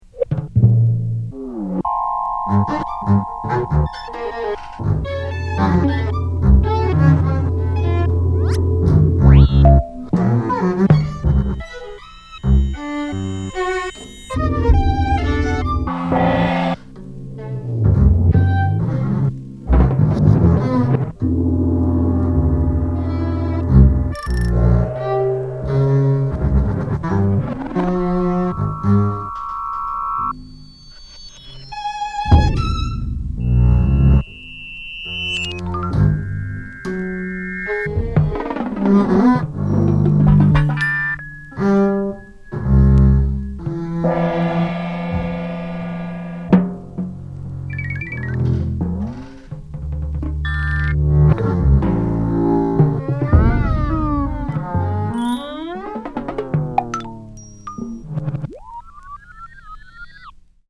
ミュージックコンクレート